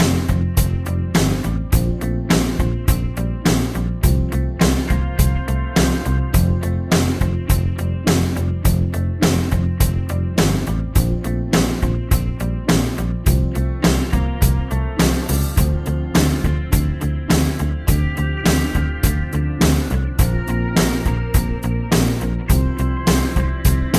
One Semitone Down Pop (1980s) 4:06 Buy £1.50